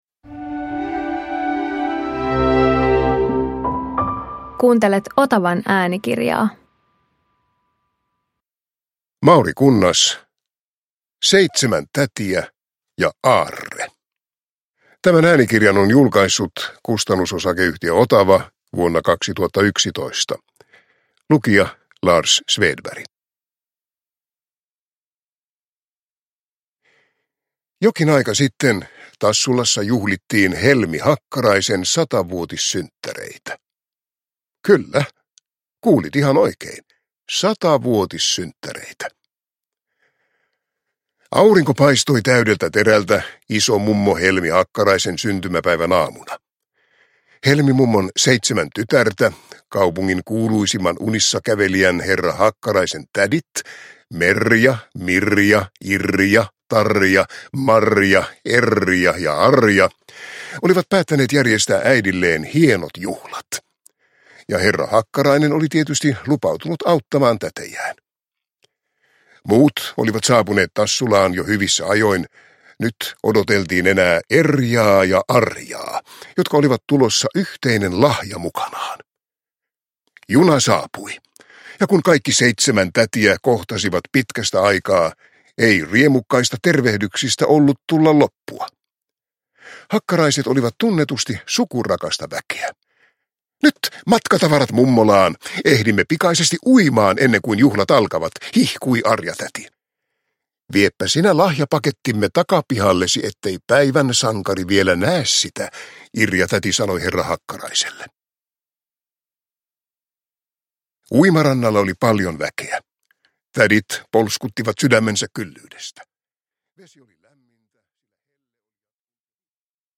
Seitsemän tätiä ja aarre – Ljudbok – Laddas ner